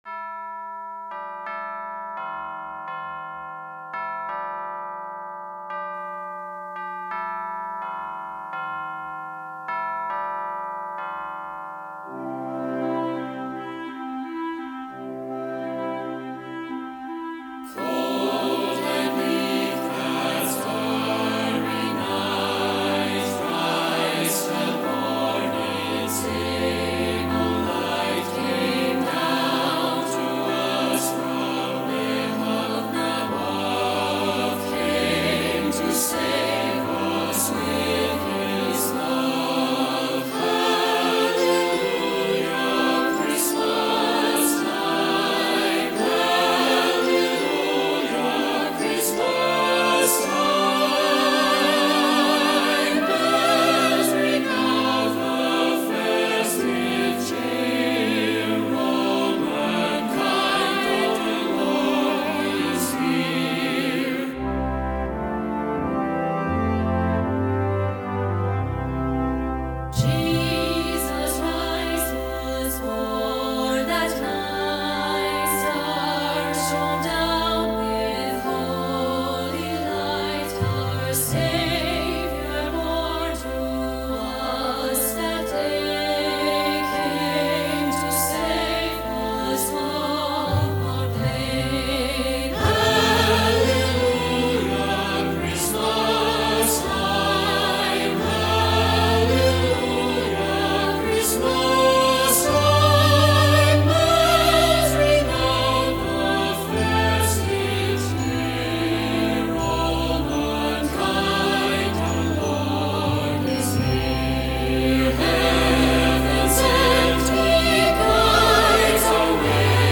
Timpani
Glockenspiel
Tubular Bells
Choir (SATB)
Concert Wind Band